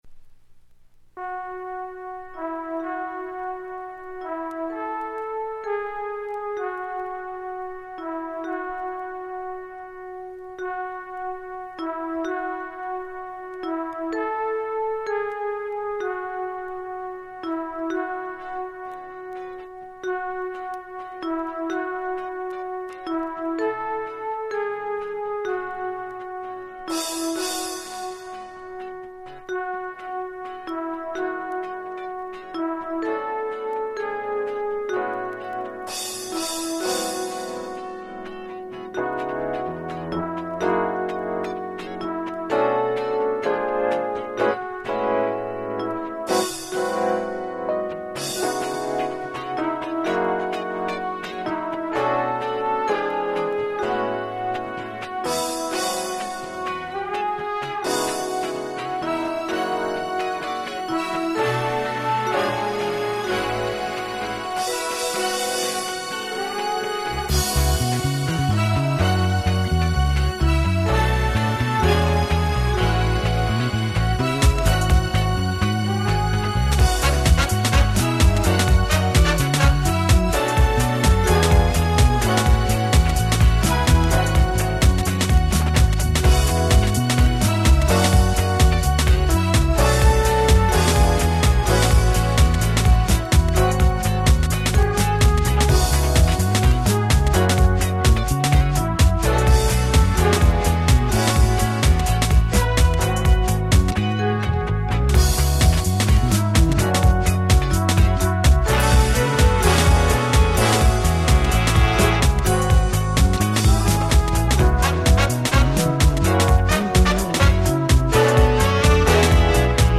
98' Smash Hit House !!